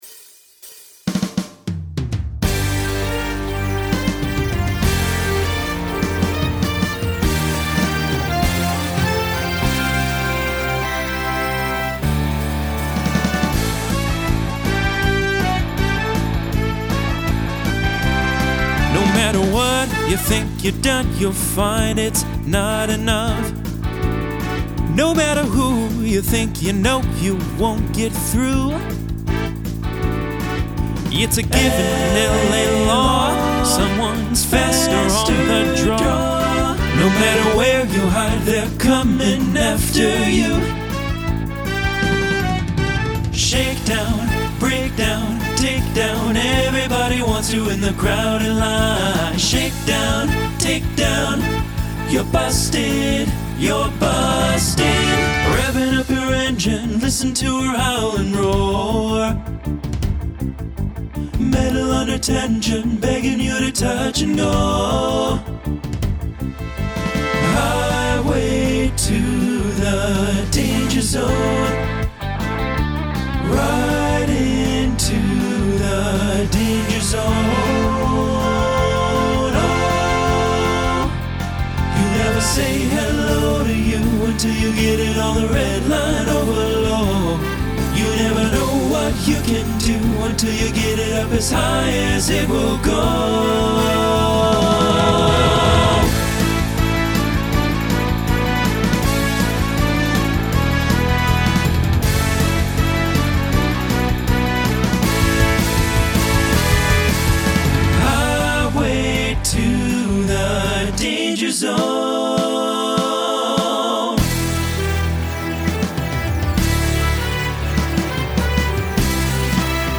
Pop/Dance , Rock
Voicing Mixed